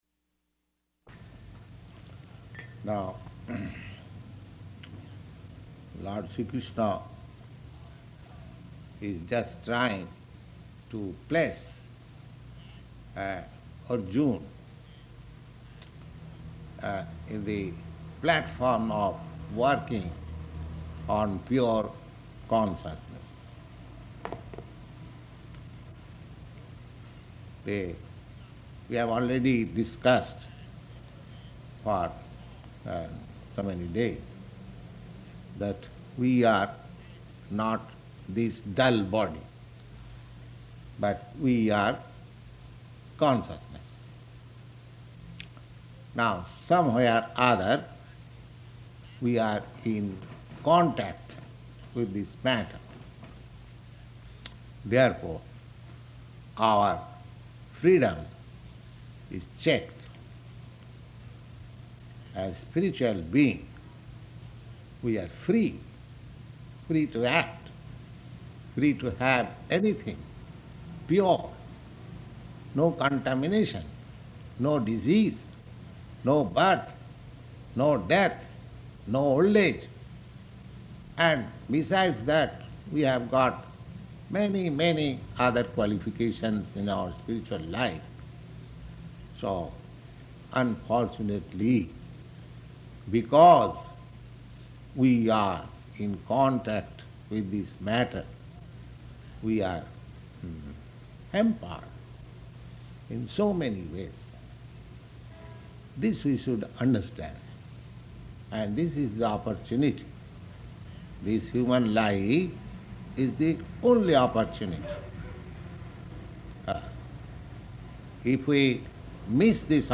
Bhagavad-gītā 2.49–51 --:-- --:-- Type: Bhagavad-gita Dated: April 5th 1966 Location: New York Audio file: 660405BG-NEW_YORK.mp3 Prabhupāda: Now Lord Śrī Kṛṣṇa is just trying to place Arjuna in the platform of working on pure consciousness.